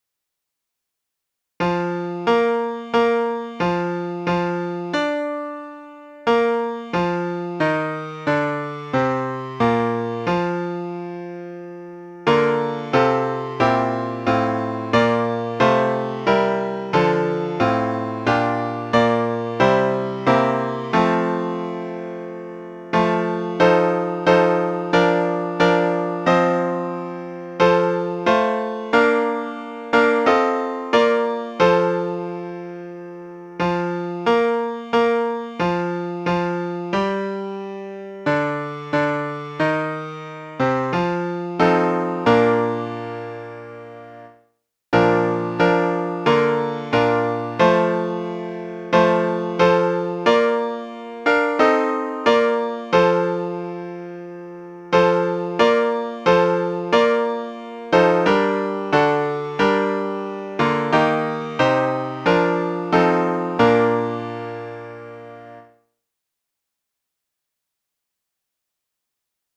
7s & 6s D with Chorus